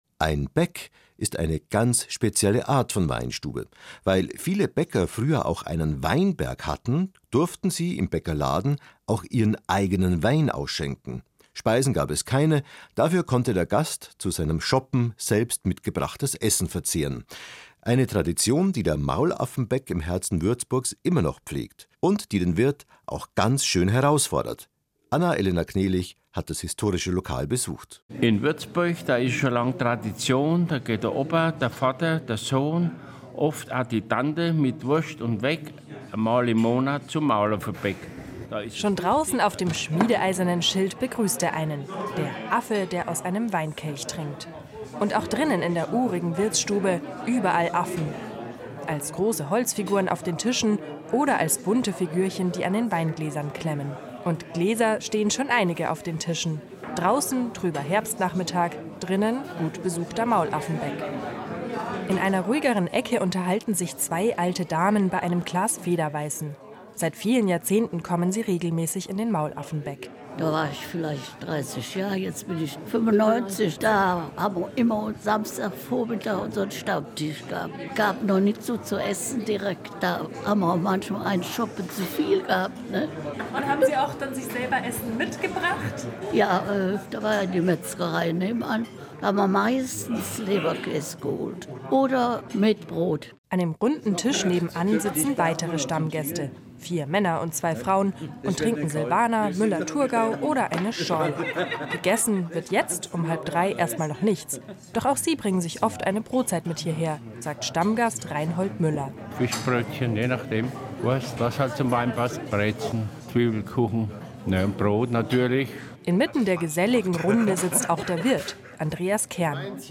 Der Bayerische Rundfunk zu Gast im Maulaffenbäck
Bei uns wird’s gesungen!
Hören Sie rein in unser Wirtshaus in diesem unterhaltsamen Porträt. Die BR-Reporter berichten gemeinsam mit unseren ältesten Gästen über die Geschichte des Maulaffenbäck, die Tradition der Bäcks und seine Besonderheiten.